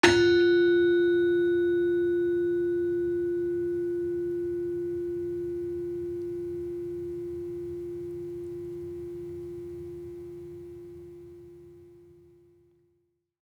Gender-4-F3-f.wav